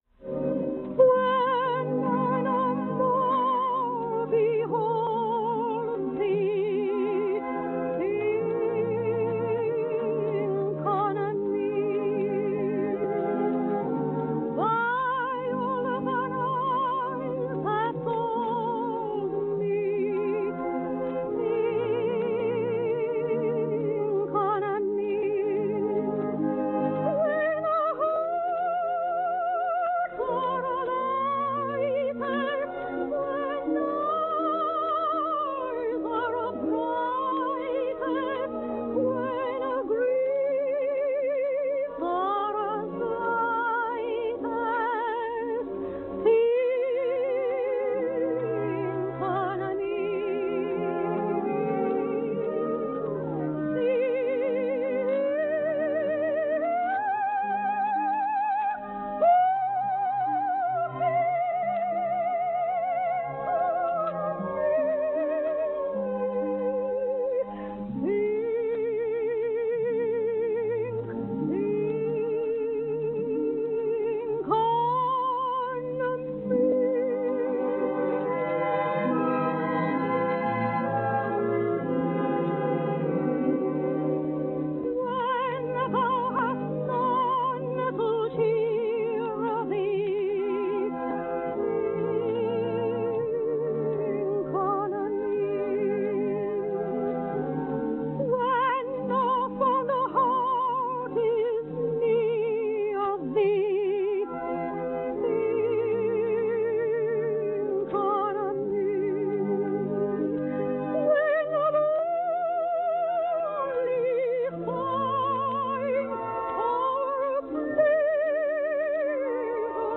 Lyric Soprano